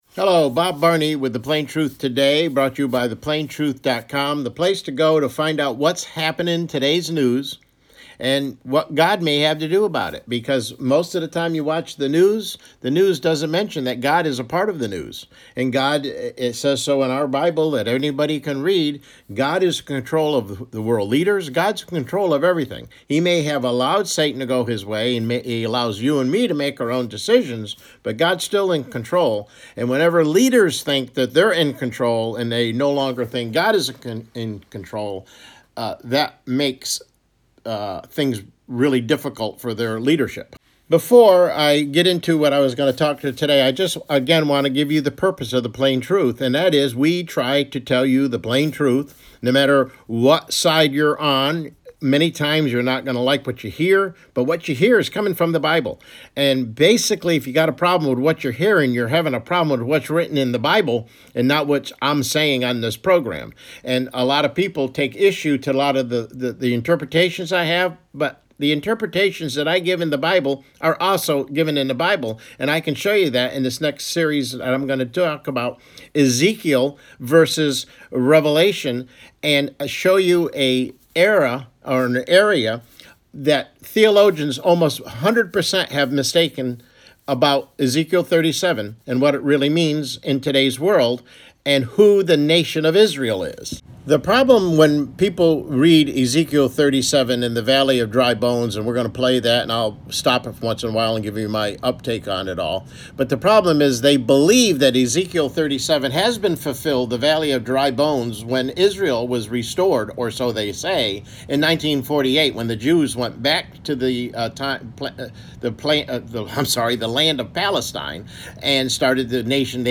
CLICK HERE TO LISTEN TO THE PLAIN TRUTH TODAY MIDDAY BROADCAST: Ezekiel 37 and 38 has not happened as is falsely believed